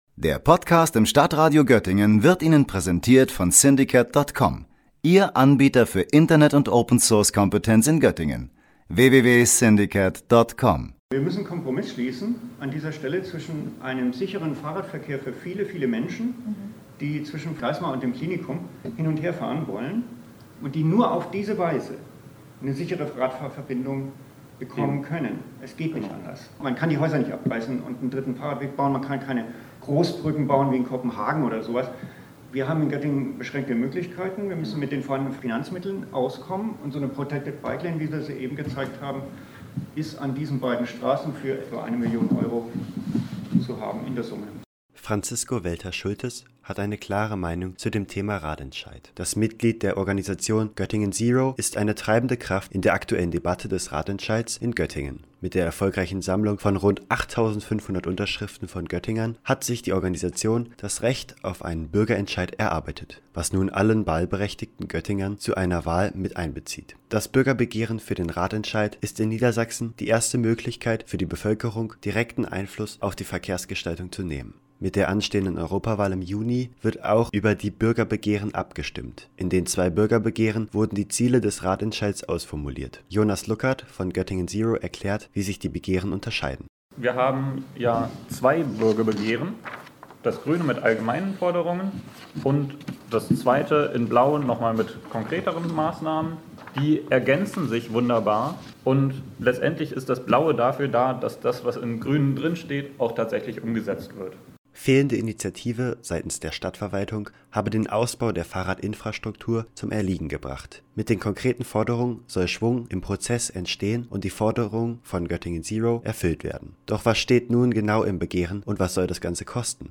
Mit einer Pressekonferenz will Göttingen Zero über die Unstimmigkeiten in der Kostenfrage beim Radentscheid aufklären. Denn dort gehen die Kostenschätzungen zwischen der Stadt Göttingen und den AktivistInnen von Göttingen Zero weit auseinander.